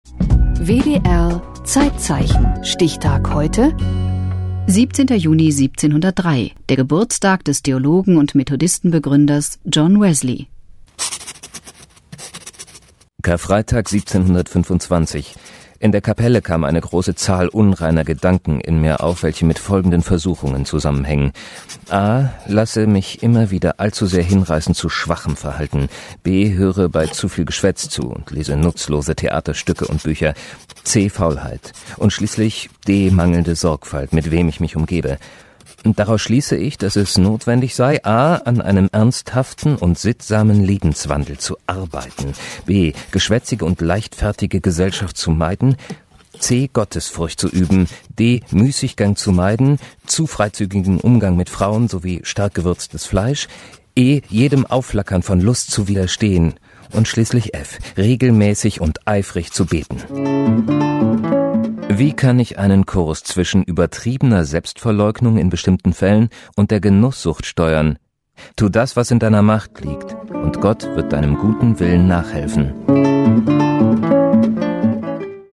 deutscher Sprecher für hörspiel, synchron, doku, voice-over, industrie, werbung, feature etc.
Sprechprobe: Sonstiges (Muttersprache):